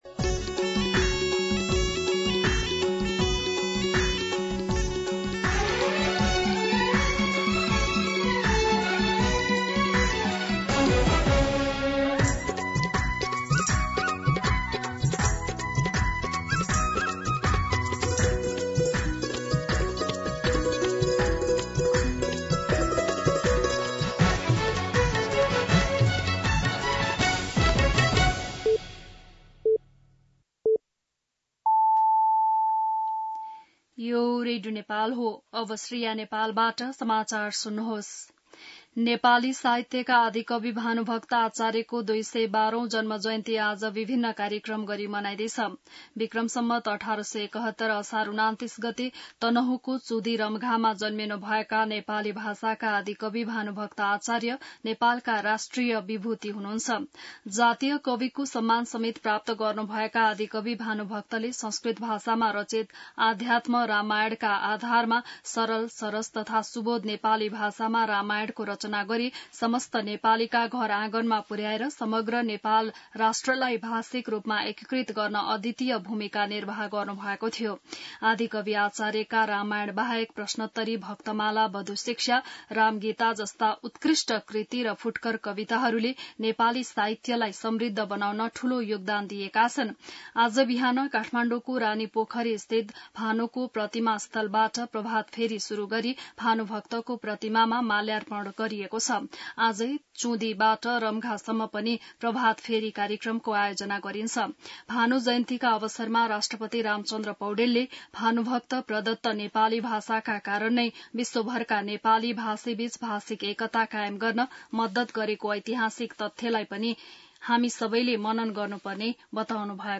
बिहान ११ बजेको नेपाली समाचार : १८ पुष , २०२६
11-am-Nepali-News.mp3